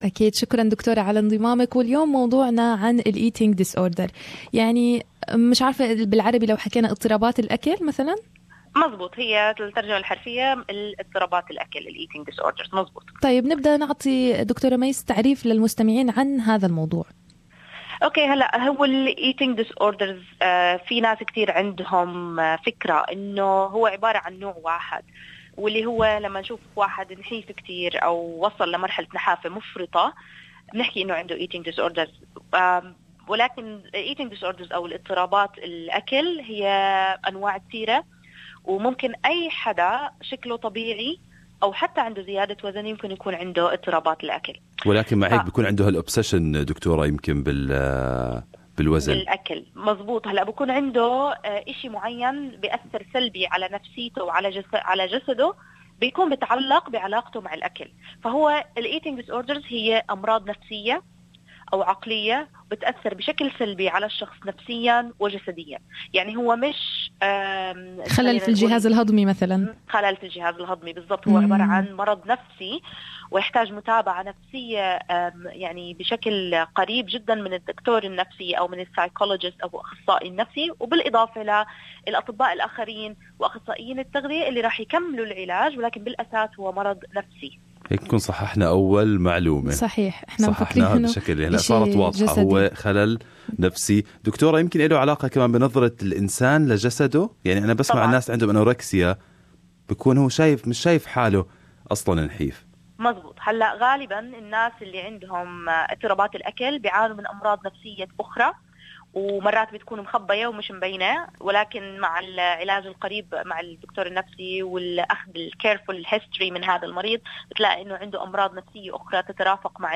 المقابلة